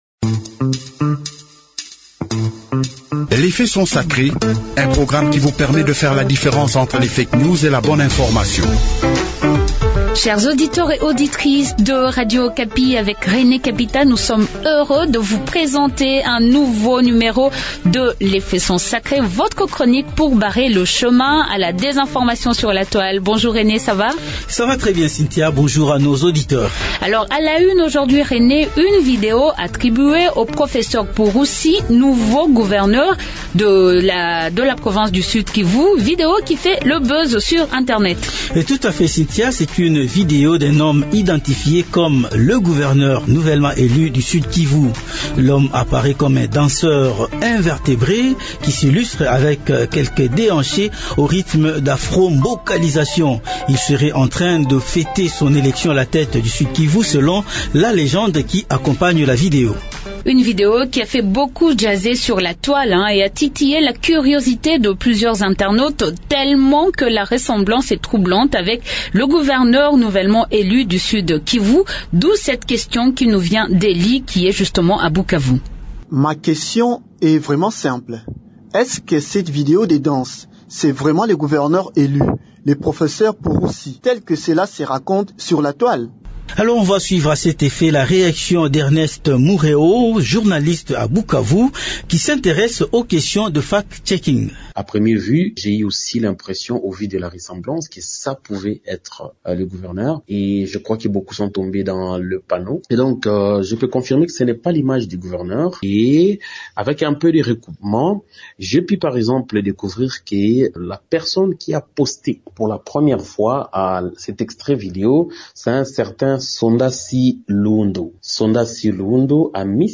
A la une aujourd’hui de votre chronique de lutte contre la désinformation « les Faits sont sacrés », une vidéo attribuée au  Professeur Jean-Jacques Purusi nouveau gouverneur élu de la province du Sud-Kivu qui fait le buzz sur Internet.